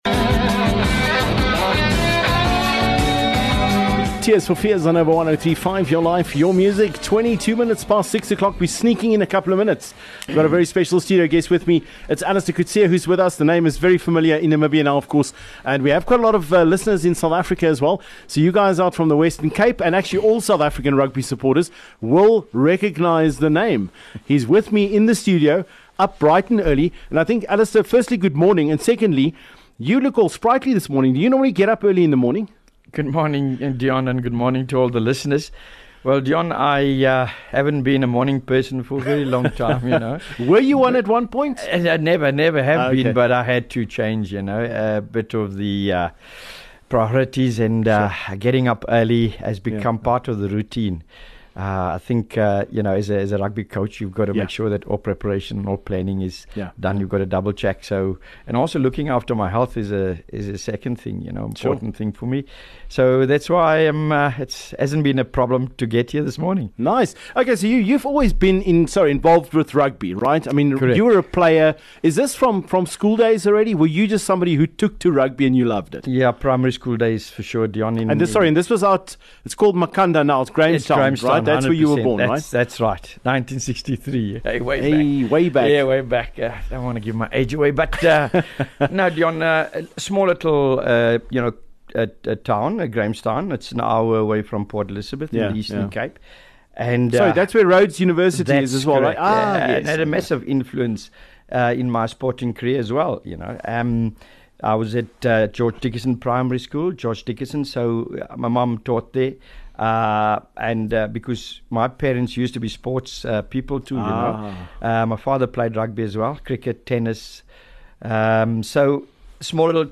12 Jul The man behind the coach - Allister Coetzee live.